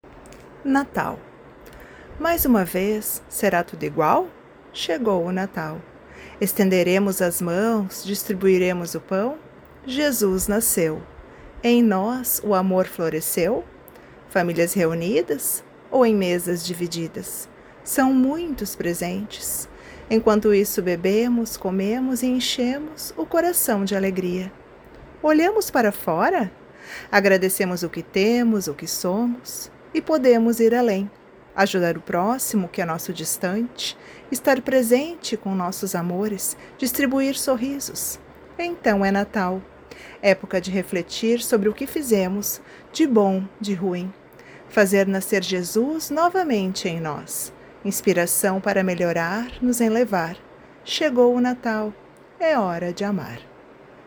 Tema mu sical Alegria ao Mundo